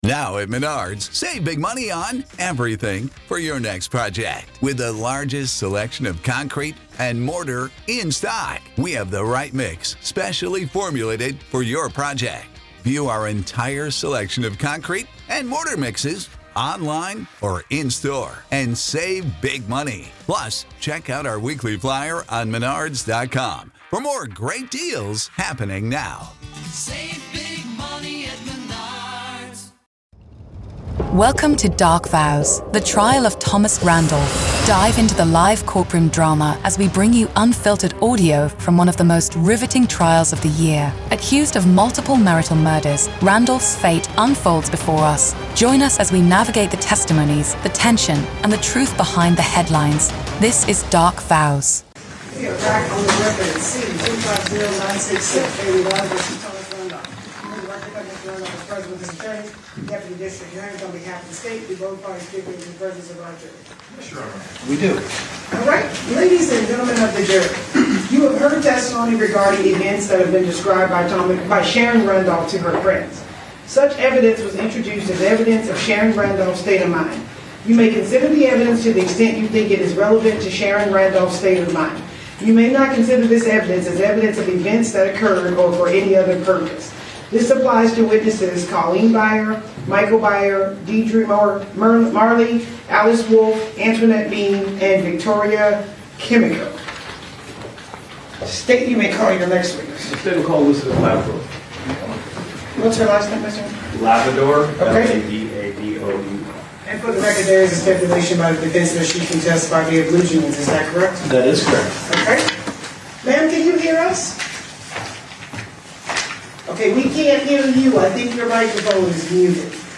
Every episode beams you directly into the heart of the courtroom, with raw, unedited audio from testimonies, cross-examinations, and the ripple of murmurs from the gallery.
We accompany the live audio with expert legal insights, breaking down the day's events, the strategies in play, and the potential implications of each revelation.